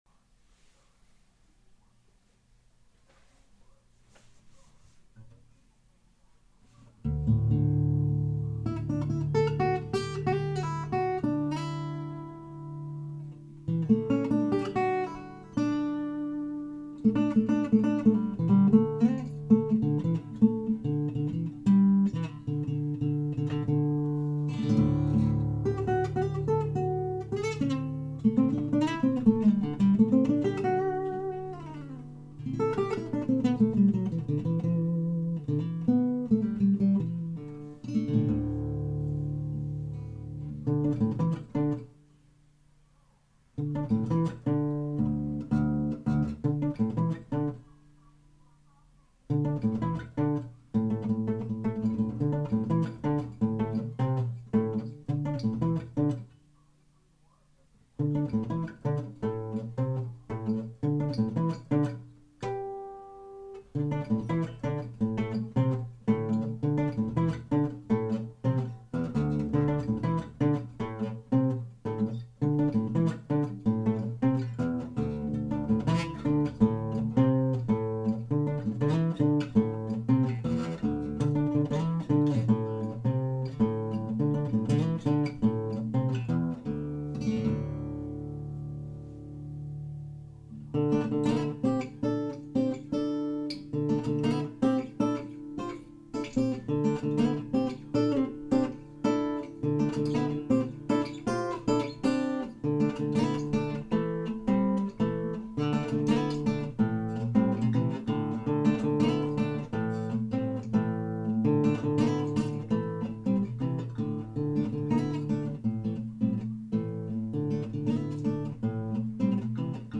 mp3 video (March 2008)   Here is an introspective and kind of groovy solo guitar piece in dropped D tuning. This is also the first recording ever on my new M-Audio Solaris microphone which I'm quite pleased with.